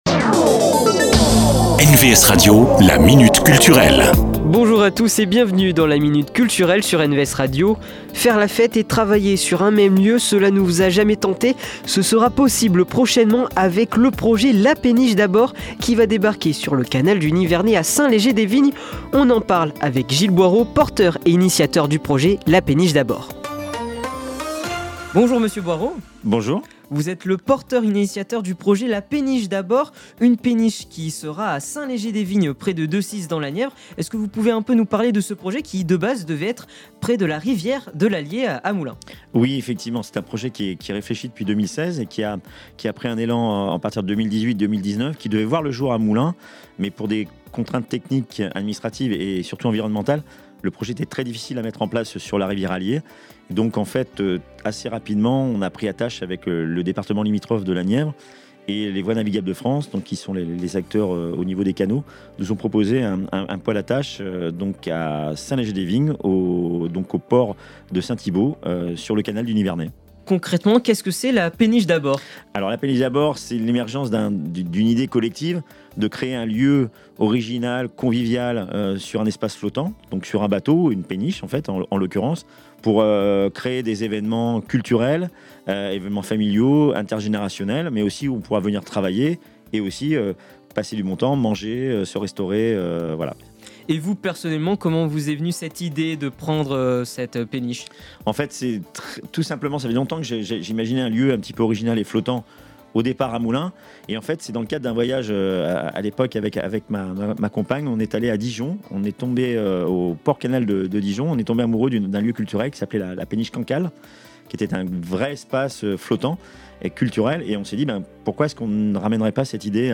La Minute Culture, rencontre avec les acteurs culturels de la région.